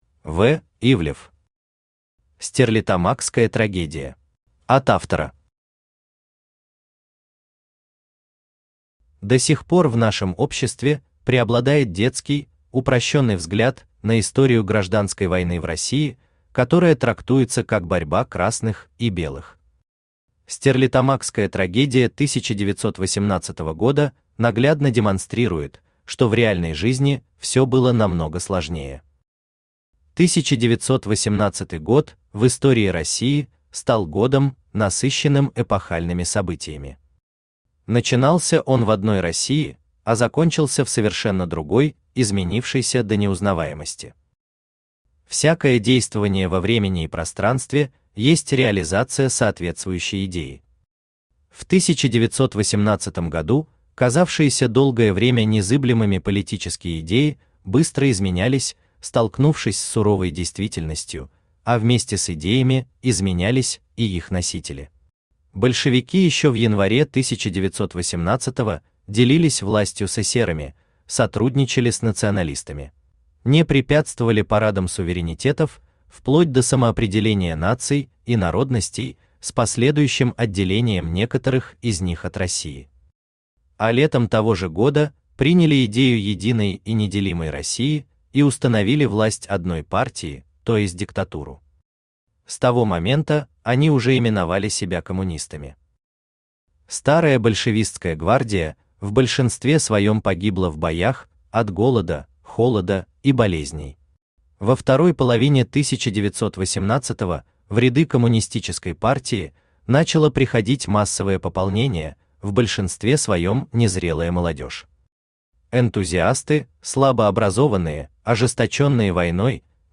Аудиокнига Стерлитамакская трагедия | Библиотека аудиокниг
Aудиокнига Стерлитамакская трагедия Автор В. В. Ивлев Читает аудиокнигу Авточтец ЛитРес.